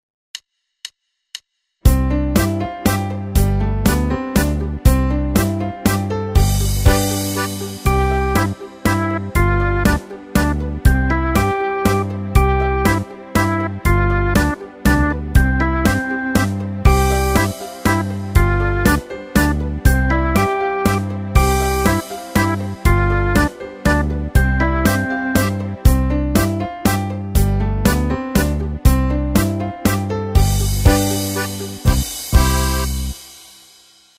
- aranżacje do ćwiczeń gry na dzwonkach: